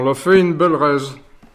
Localisation Xanton-Chassenon
Catégorie Locution